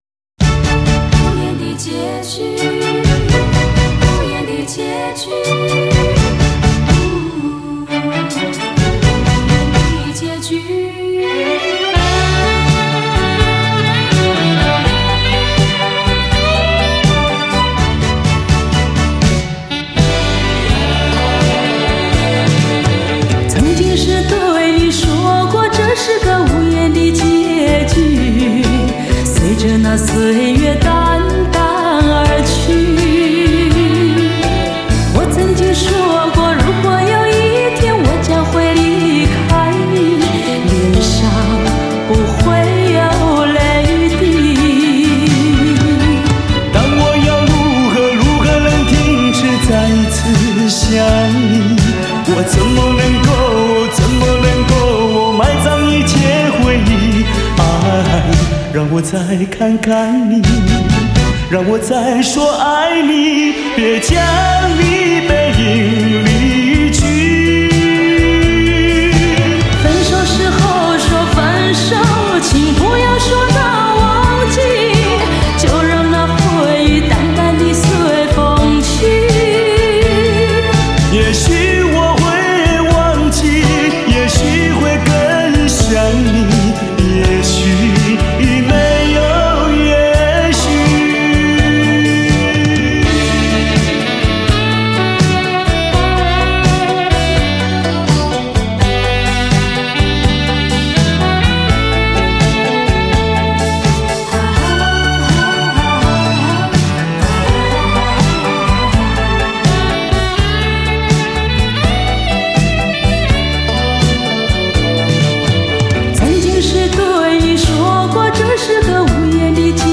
黑胶雷射系列